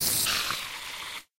spiderdeath.ogg